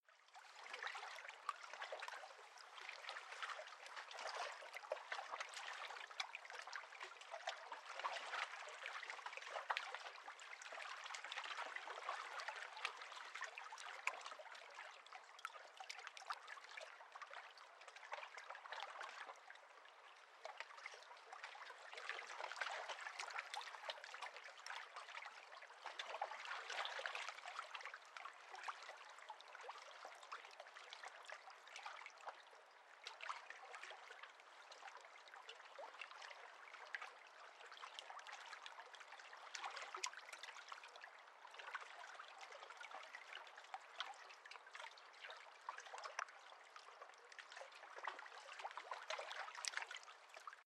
Water.mp3